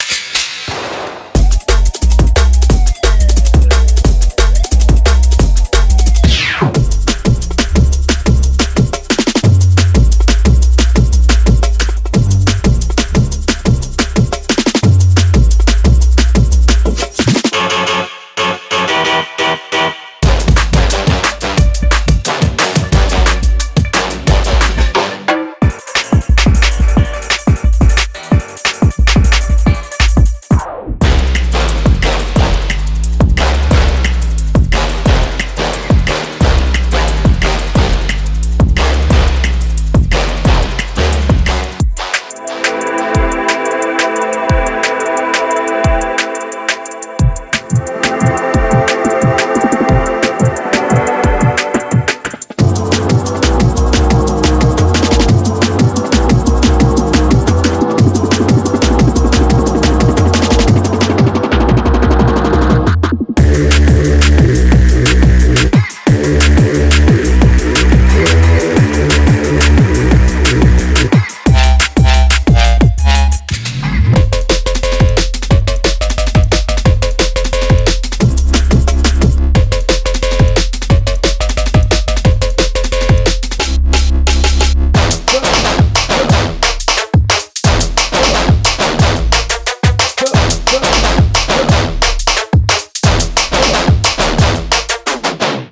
Genre:Drum and Bass
デモサウンドはコチラ↓
41 Bass Loops
45 Drum Loops
40 Music Loops
20 Top/Perc Loops
30 Drum Hits